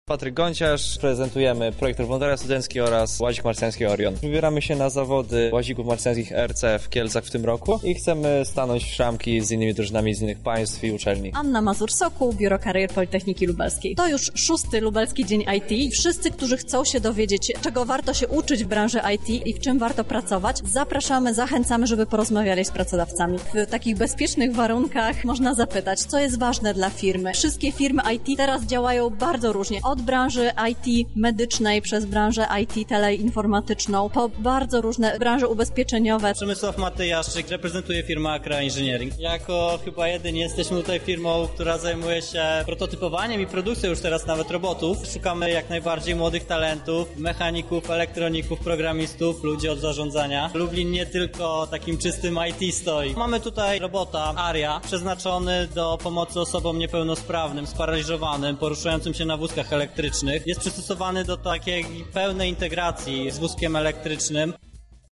Na miejscu był nasz reporter.
Relacja